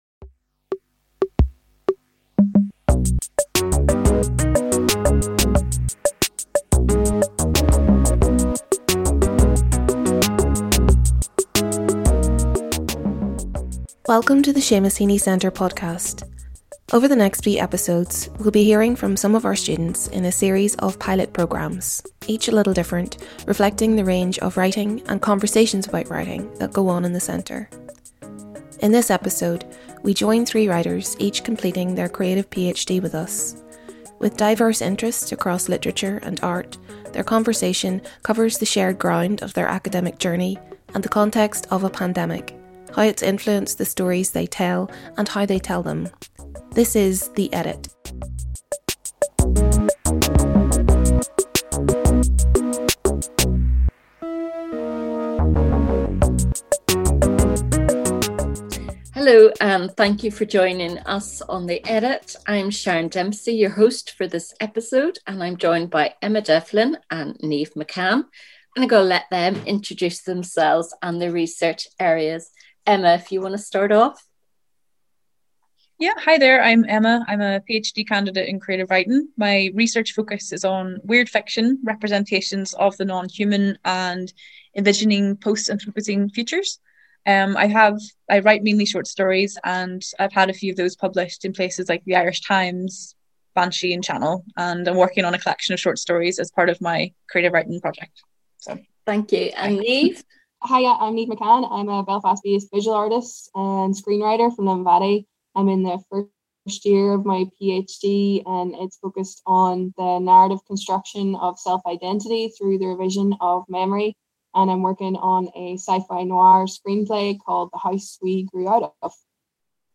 In this episode, we join three writers, each completing their creative PhD with us. With diverse interests across literature and art, their conversation covers the shared ground of their academic journey, and the context of a pandemic - how it has influenced the stories they tell, and how they tell them.